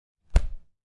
计算机 " Leptop键盘
描述：点击笔记本电脑键盘的声音。